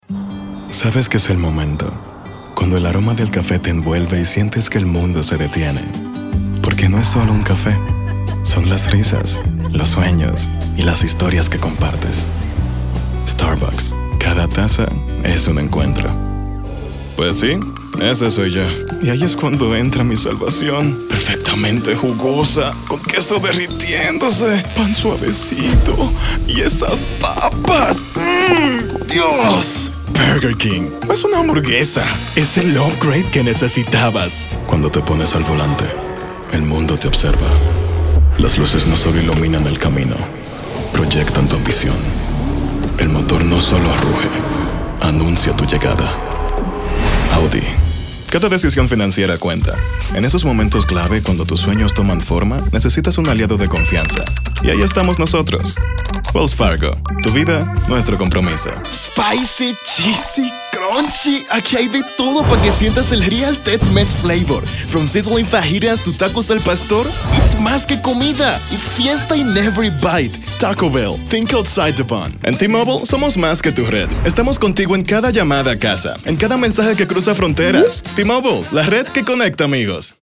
Spanish Commercial Demo
•   Dominican Voice Actor    •   Source Connect 4 Ready    •   Broadcast Quality Audio
Microphone: Sennheiser MKH 416 / Rode SS